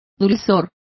Complete with pronunciation of the translation of sweetness.